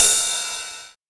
• Ride Cymbal One Shot A Key 04.wav
Royality free ride single hit tuned to the A note. Loudest frequency: 7864Hz
ride-cymbal-one-shot-a-key-04-STV.wav